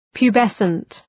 {pju:’besənt}